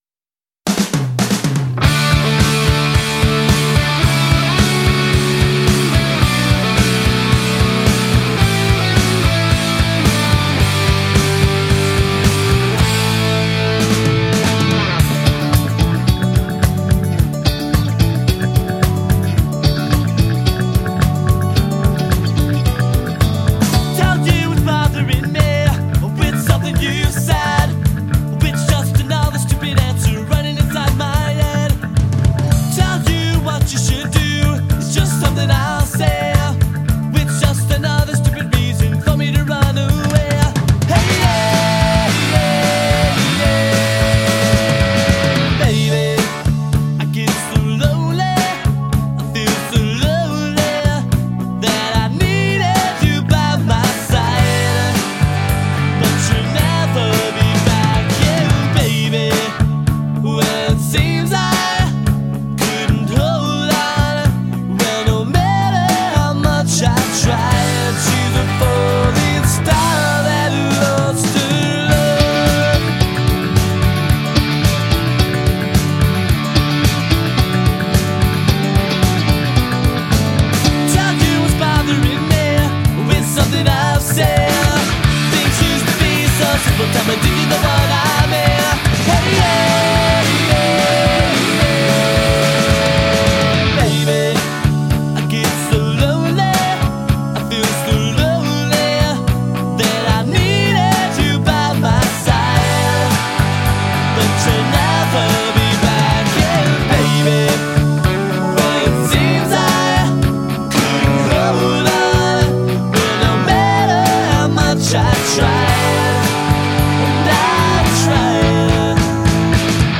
emo-core, pop punk